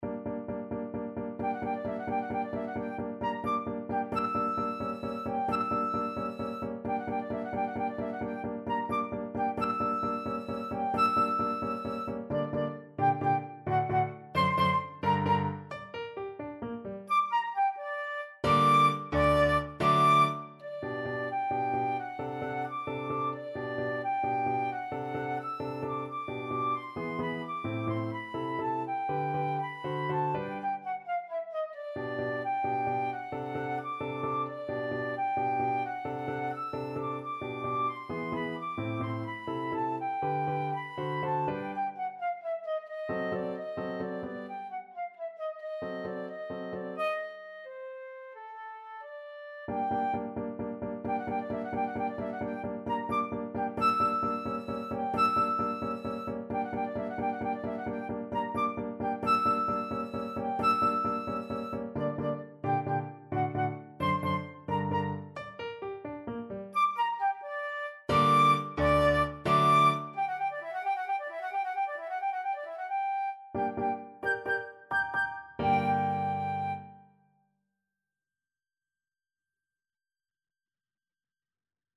Ballade from 25 Progressive Pieces Flute version
Flute
G minor (Sounding Pitch) (View more G minor Music for Flute )
Allegro con brio (.=104) .=88 (View more music marked Allegro)
3/8 (View more 3/8 Music)
Classical (View more Classical Flute Music)